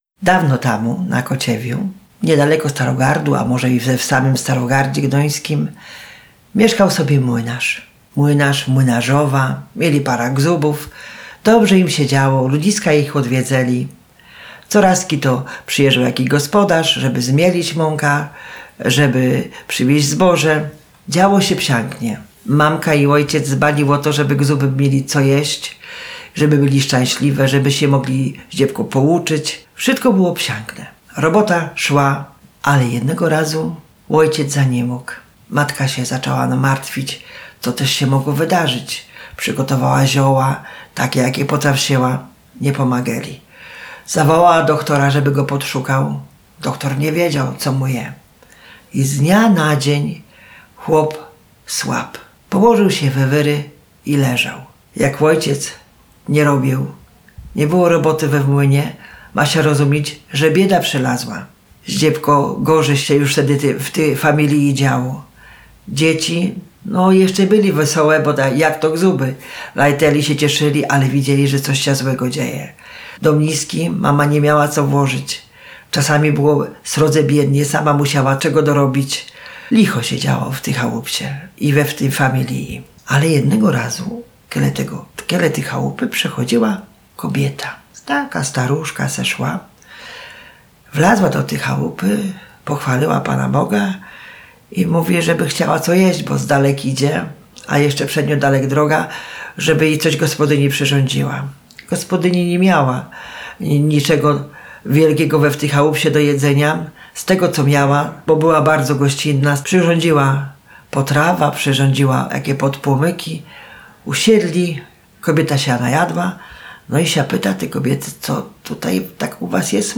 Bajka „O młynarzu”.